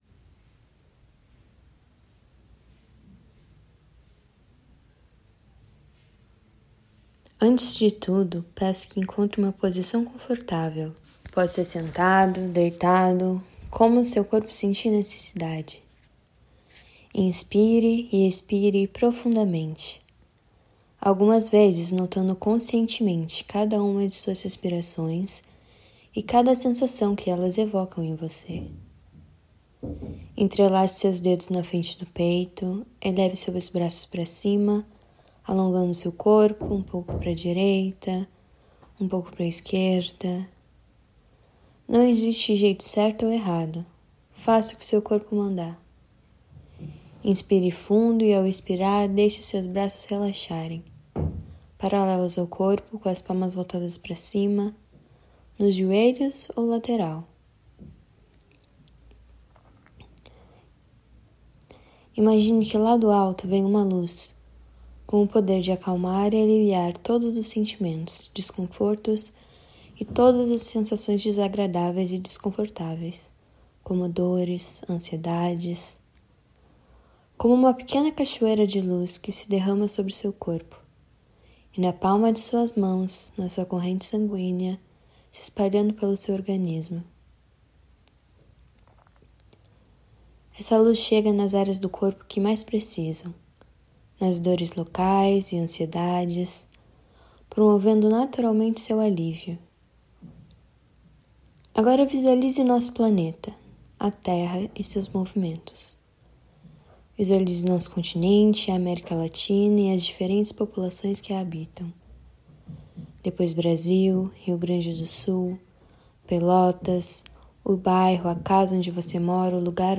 Áudio da meditação guiada: Audio.relaxamento